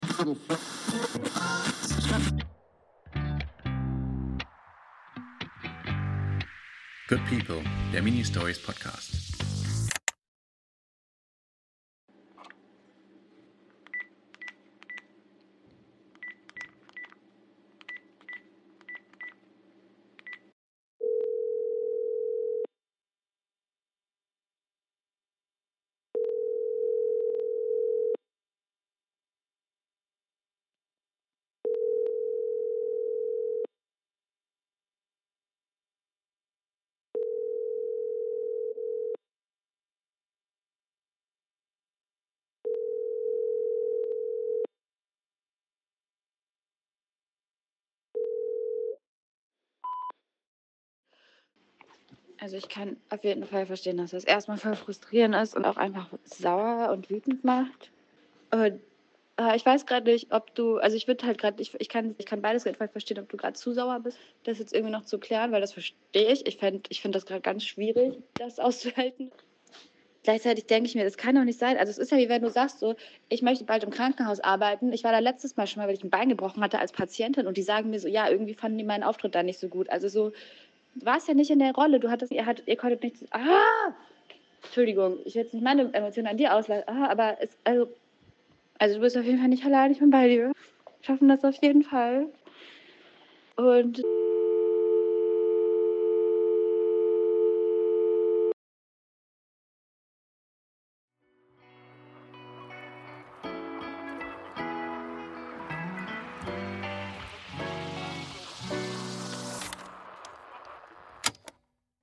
Es tutet. Einmal, zweimal, dreimal – dann der Piep des Anrufbeantworter. Eine Stimme beginnt zu sprechen. Zögerlich, suchend.